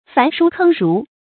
燔书坑儒 fán shū kēng rú
燔书坑儒发音
成语注音 ㄈㄢˊ ㄕㄨ ㄎㄥ ㄖㄨˊ